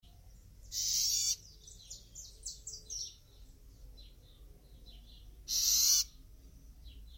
Southern House Wren (Troglodytes musculus)
Sonido de alarma
Life Stage: Adult
Location or protected area: La Merced
Condition: Wild
Charrasca-alarma.mp3